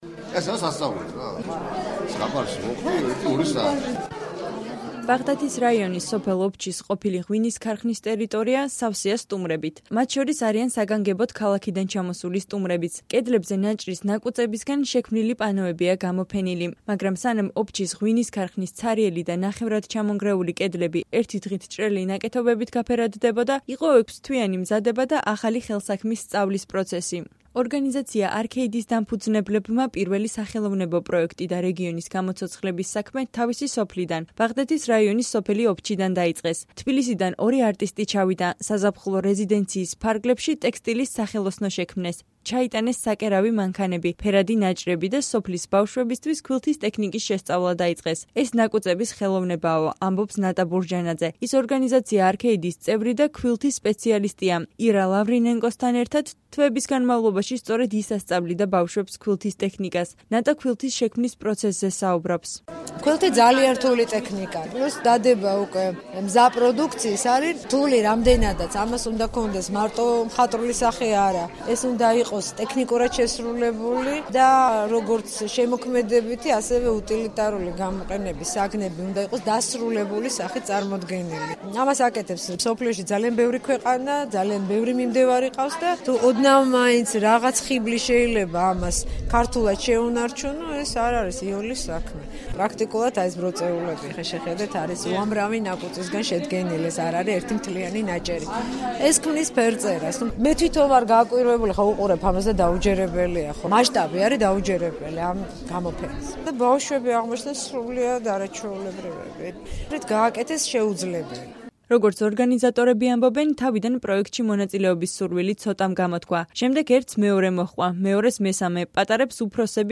„ესაა სასწაული, ზღაპარში მოვხვდი ერთი-ორი საათით...“ – ისმის დარბაზში დამთვალიერებელთა ხმები...